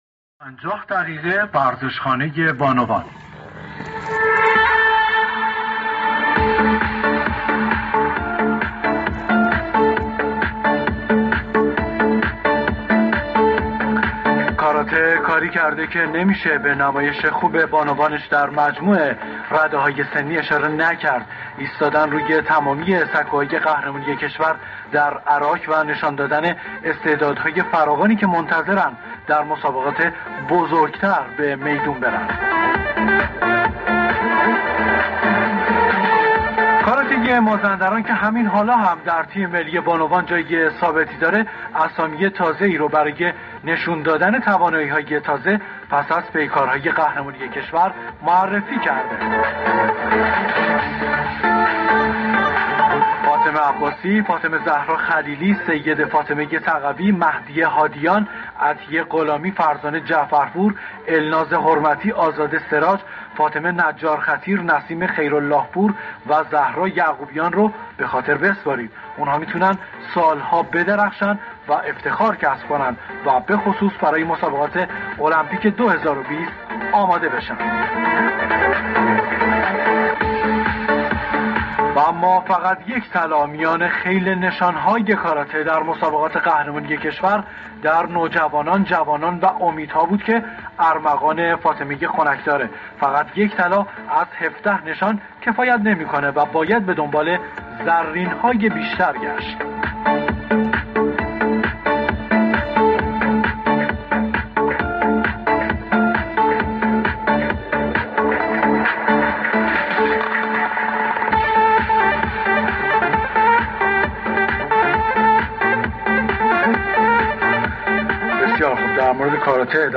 مصاحبه صوتی